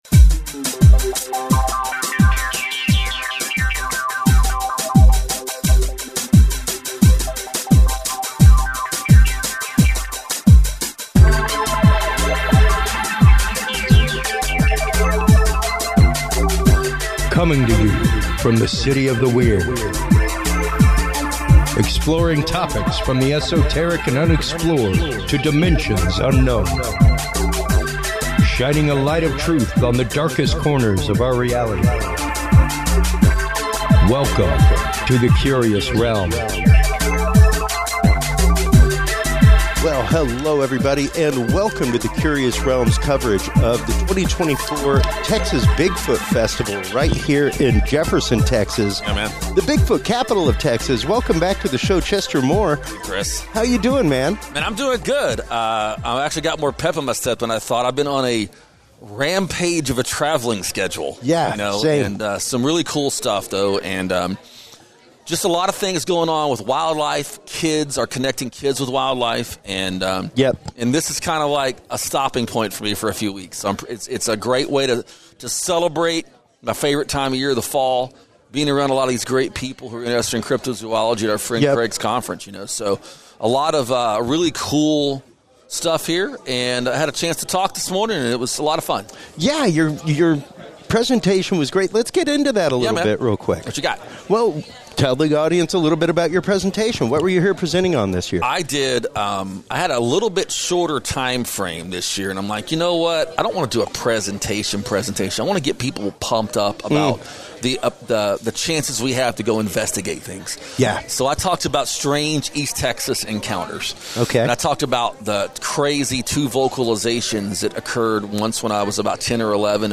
CR Ep 151: On Location at 2024 Texas Bigfoot Conference - Curious Realm